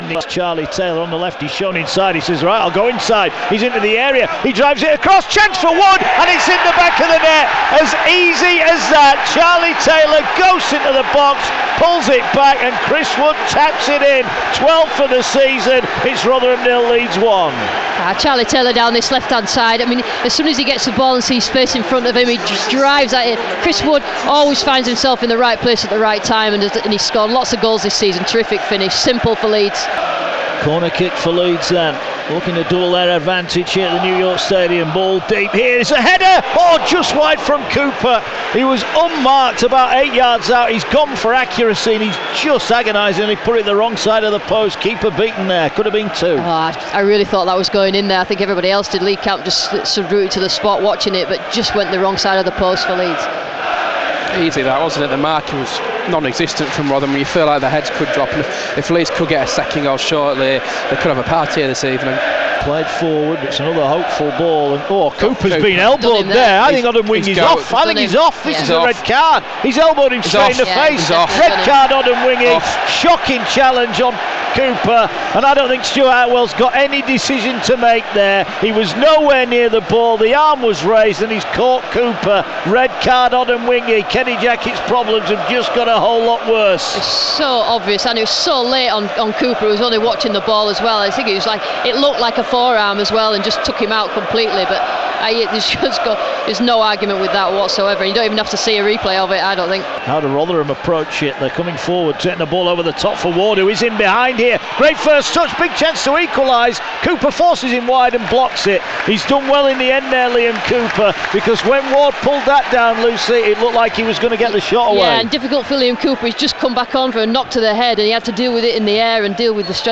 Rotherham 1-2 Leeds full highlights